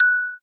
noteblock_bell.wav